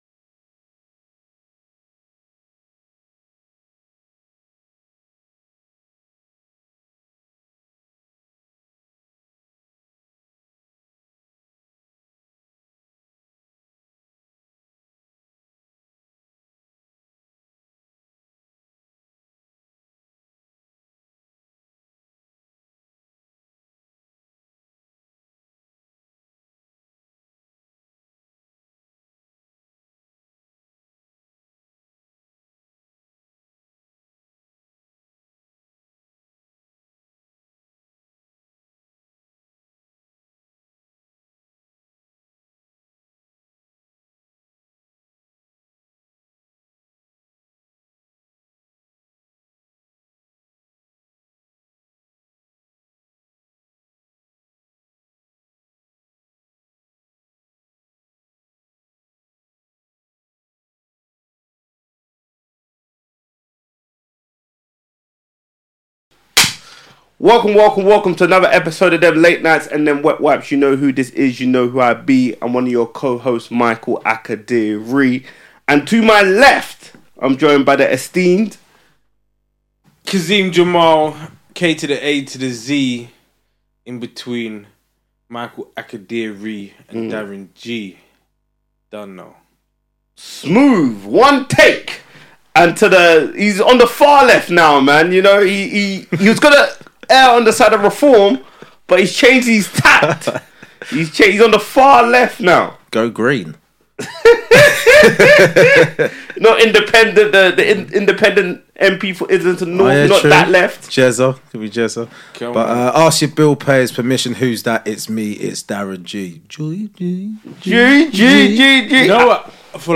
No guests, just the three guys chatting nonsense and some sense, with a big announcement.